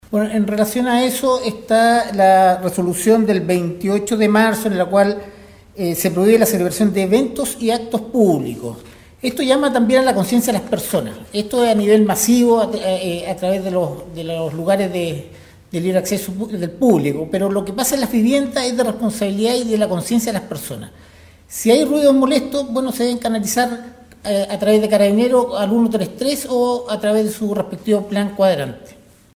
En el habitual punto de prensa que realizan las autoridades del Gobierno Regional de Atacama, Nostálgica tuvo la oportunidad de efectuar algunas preguntas, las que en esta ocasión estuvieron dirigidas a la seguridad ciudadana.
Se le indicó al Jefe de la Defensa Nacional de Atacama que se necesita un fono donde las personas puedan llamar, ante lo cual Heyermann apunto a seguir el conducto regular, que sería el fono de emergencia de Carabineros: